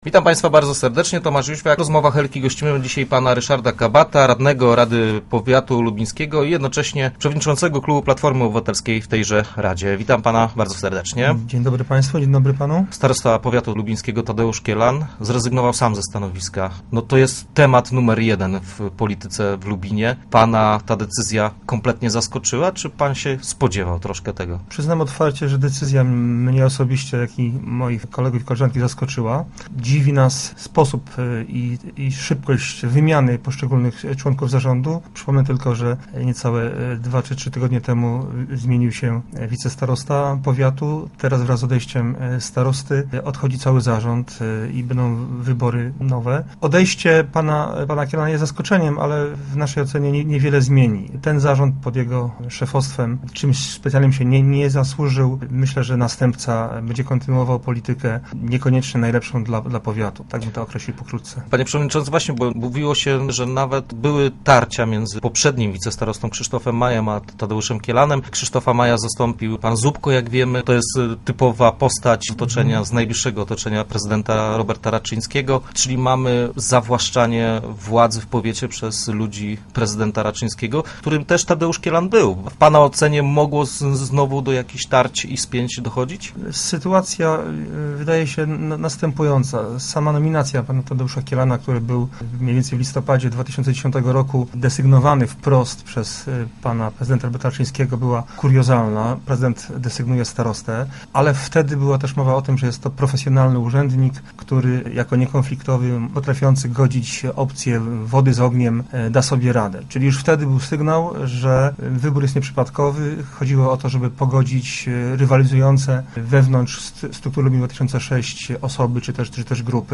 Zaskoczeni takim obrotem sprawy są radni opozycyjnej Platformy Obywatelskiej. Przewodniczący klubu tej partii, Ryszard Kabat, był naszym gościem.